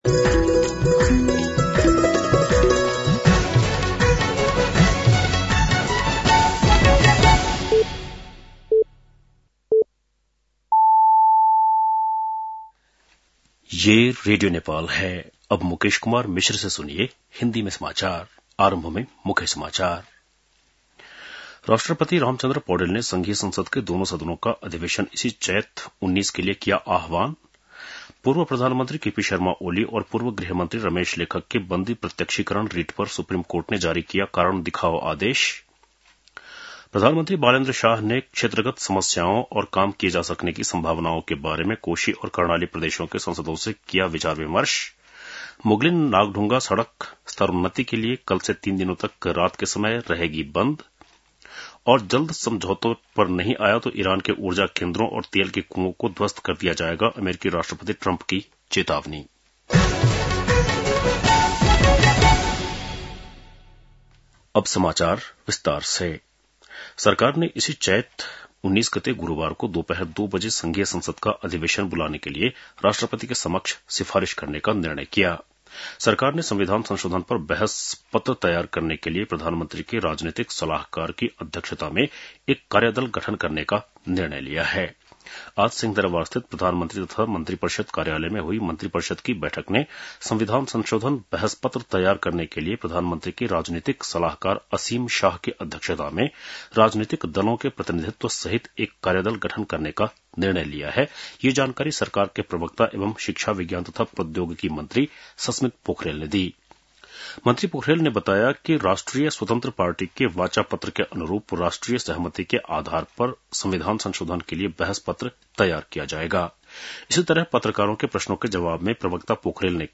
बेलुकी १० बजेको हिन्दी समाचार : १६ चैत , २०८२